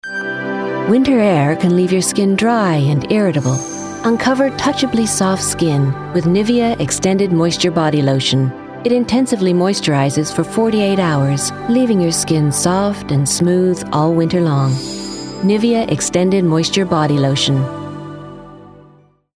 40/50's North American, Versatile/Reassuring/Natural